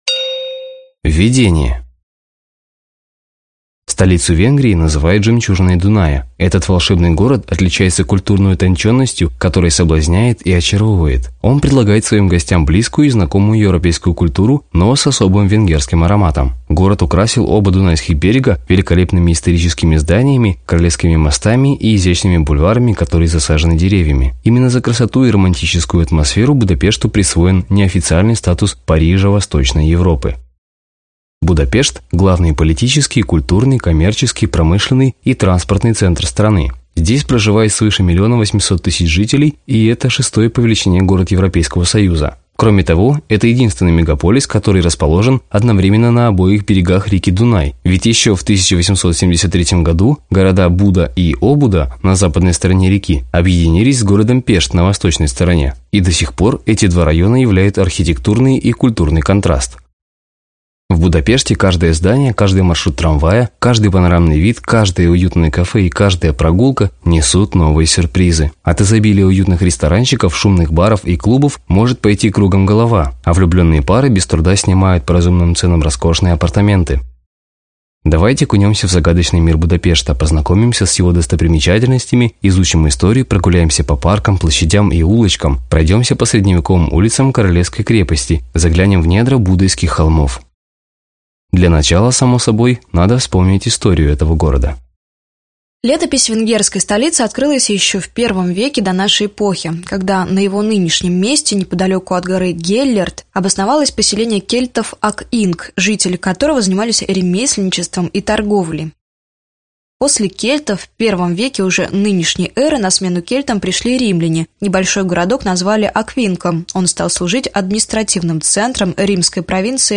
Аудиокнига Будапешт | Библиотека аудиокниг